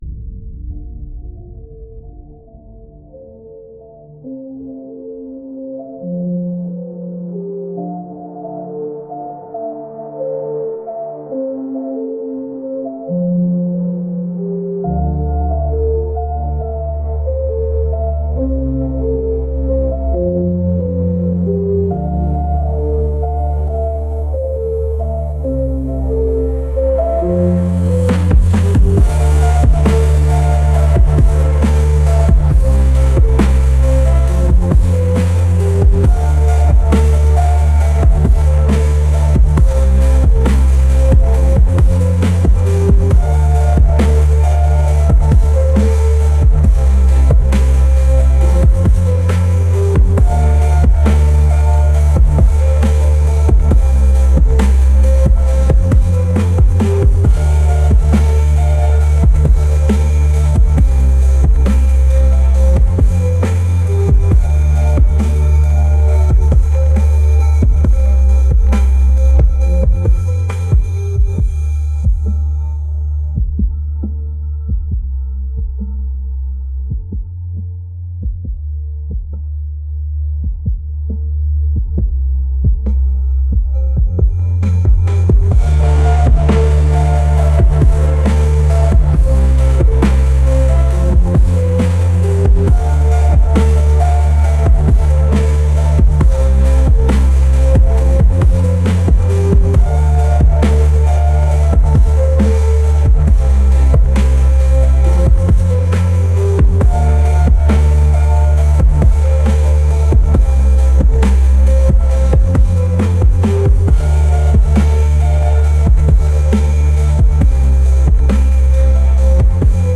• Жанр: Electronic